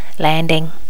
Normalize all wav files to the same volume level.
landing.wav